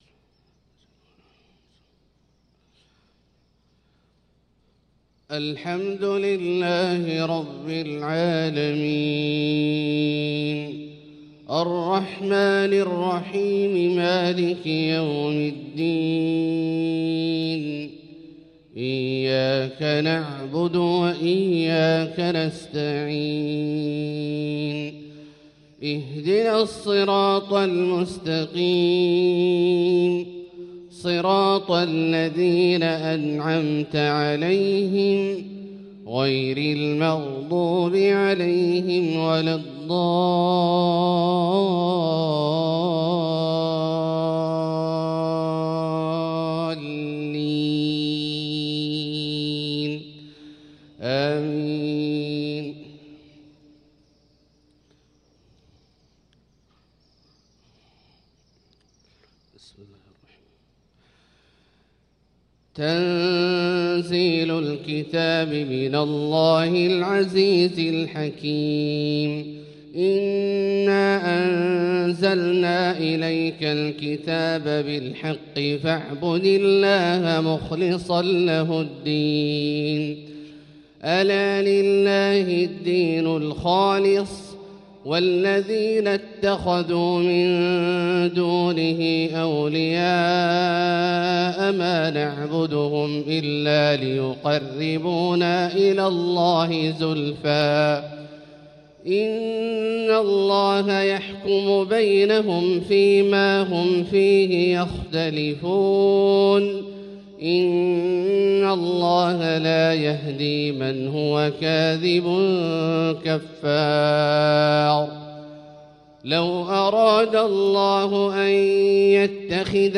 صلاة الفجر للقارئ عبدالله البعيجان 17 شعبان 1445 هـ